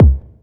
Index of /90_sSampleCDs/Club_Techno/Percussion/Kick
Kick_23.wav